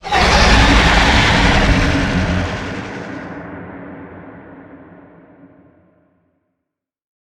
File:Sfx creature squidshark callout 03.ogg - Subnautica Wiki
Sfx_creature_squidshark_callout_03.ogg